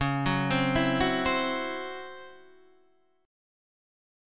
C# Minor Major 7th Double Flat 5th